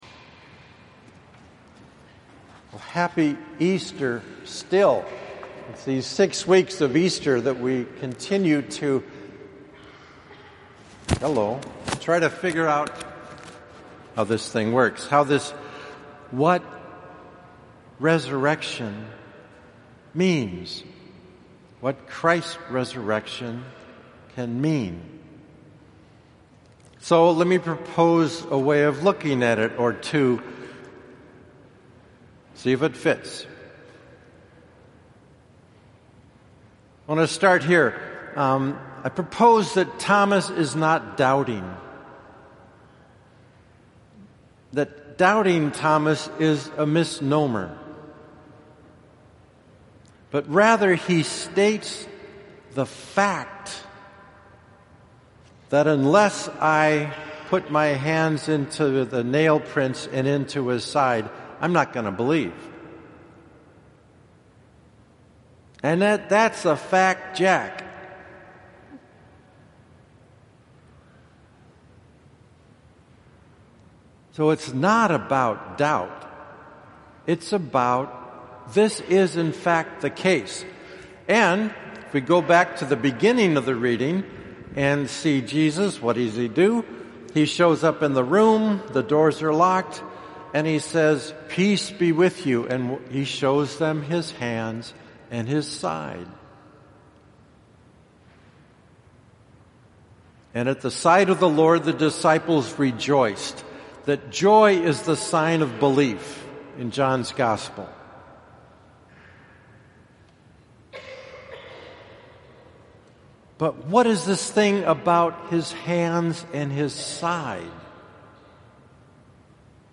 Homily for 2nd Sunday of Easter (Divine Mercy)